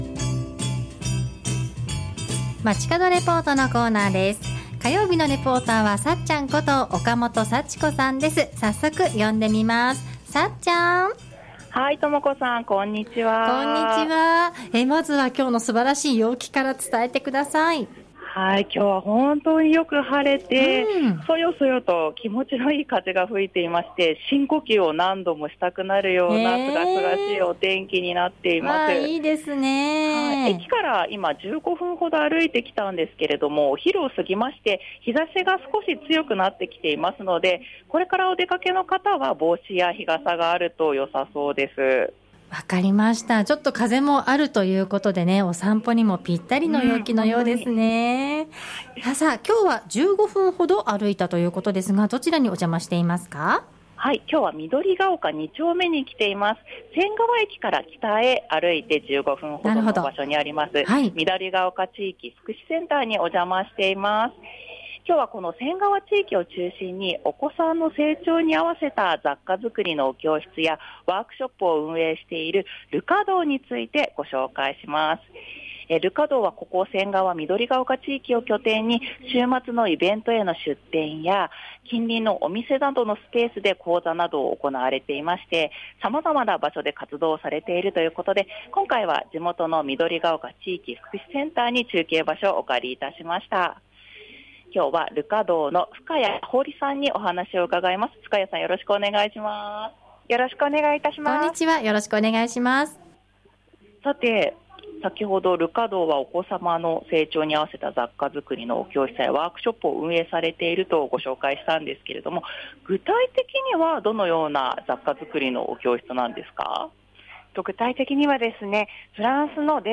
中継は緑が丘2丁目にある緑が丘地域福祉センターから、仙川地域を中心にお子さんの成長に合わせた雑貨づくりのお教室やワークショップを運営している「Le cadeau（ル・カドー）」についてご紹介しました。